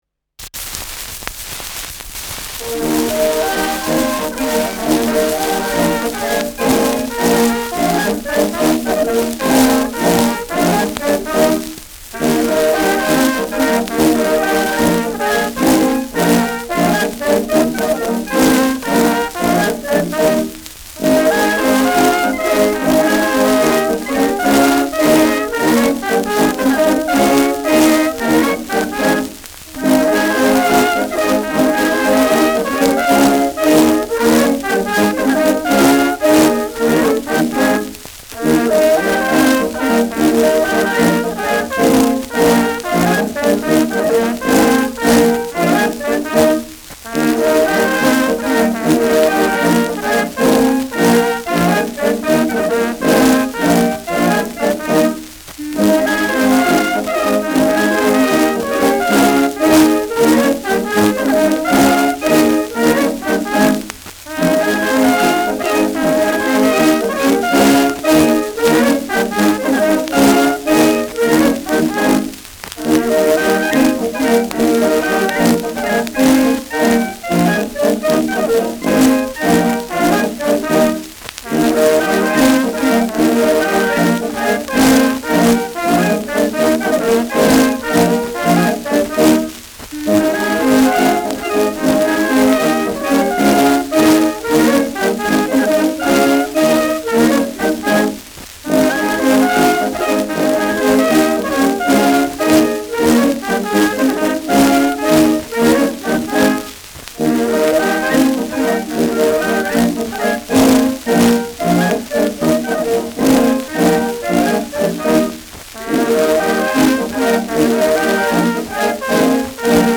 Schellackplatte
präsentes Rauschen : präsentes Knistern : abgespielt : leichtes Leiern
Dachauer Bauernkapelle (Interpretation)